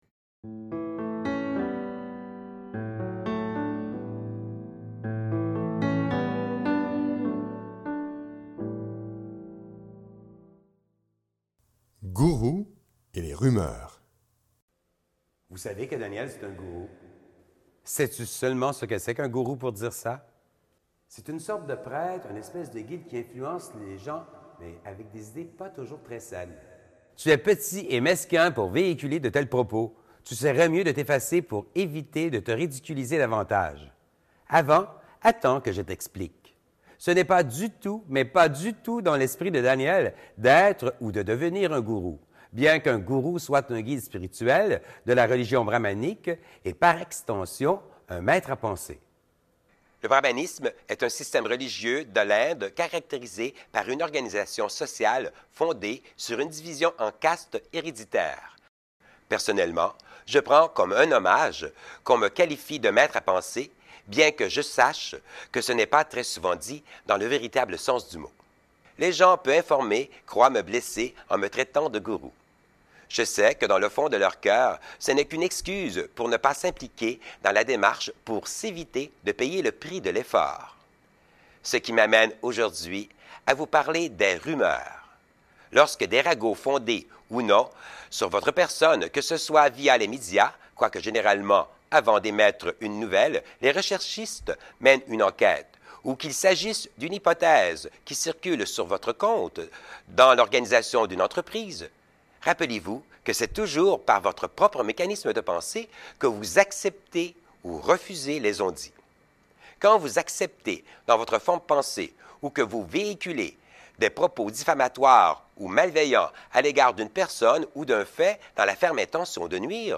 Diffusion distribution ebook et livre audio - Catalogue livres numériques
De petites capsules audio inspirantes, motivantes et réconfortantes.